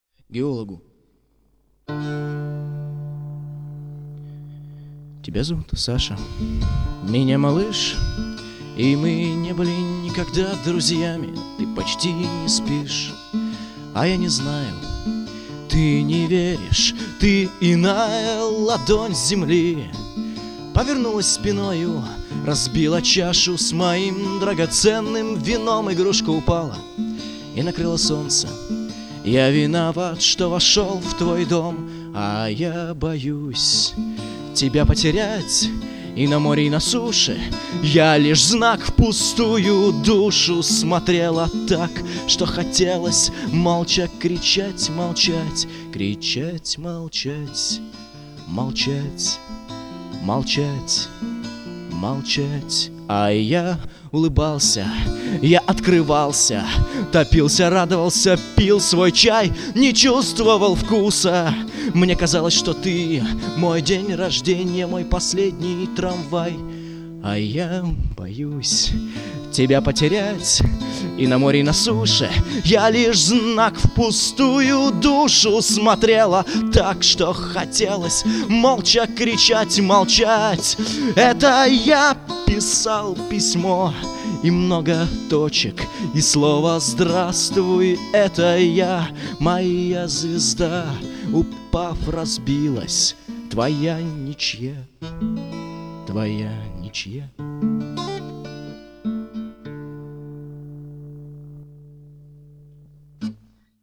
• Жанр: Бардрок